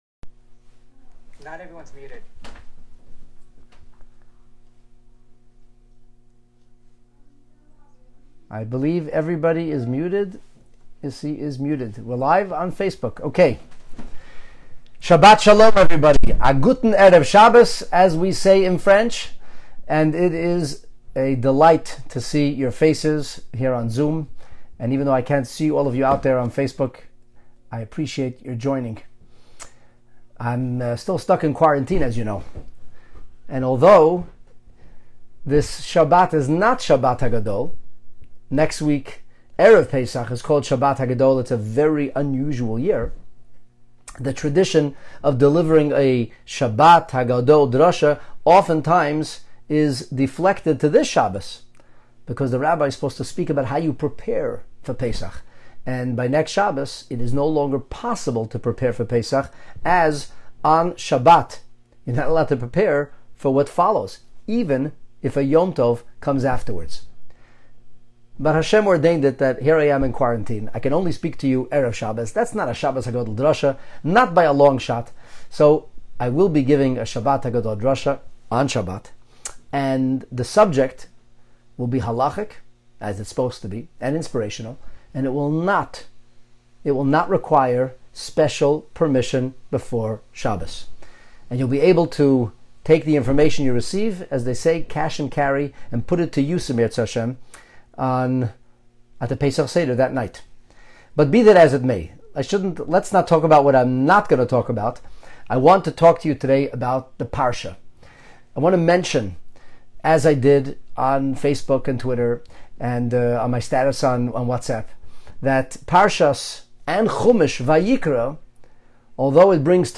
Pre Shabbat Vayikra Sermon - The Animal Within You